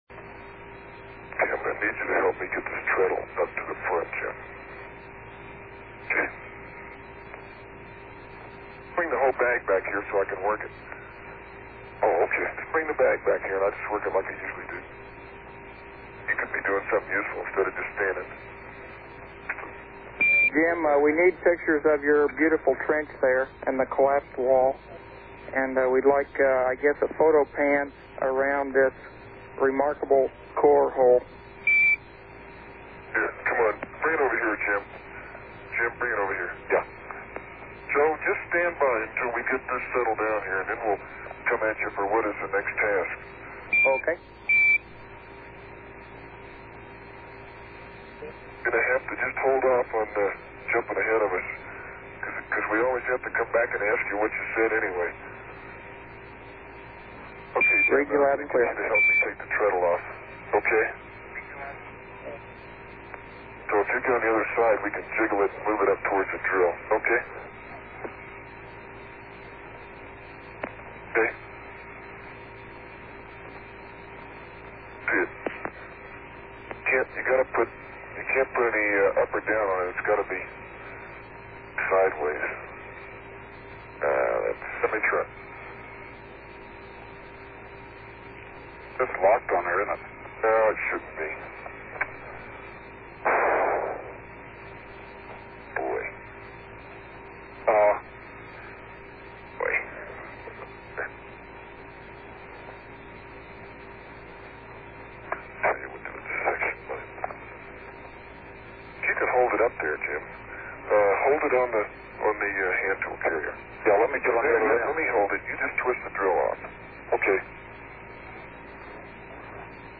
Niederschriften des Funkverkehrs, Kommentare und Erläuterungen zu den Apollo-Mondlandungen